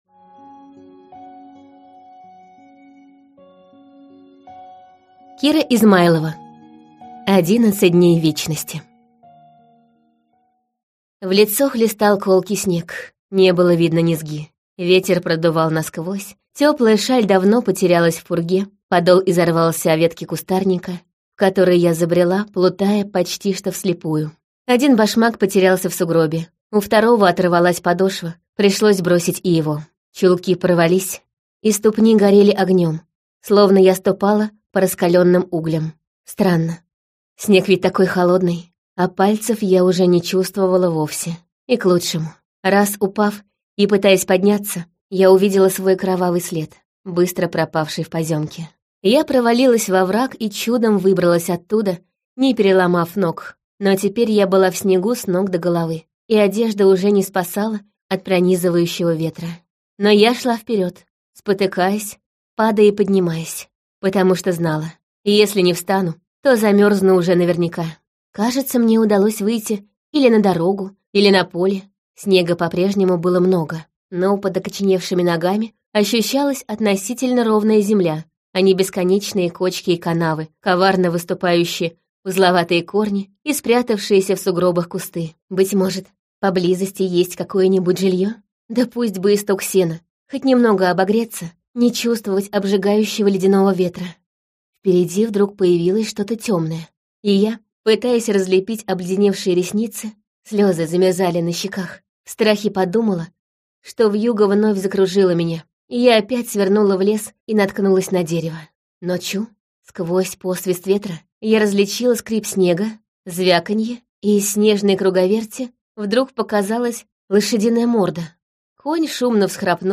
Аудиокнига Одиннадцать дней вечности - купить, скачать и слушать онлайн | КнигоПоиск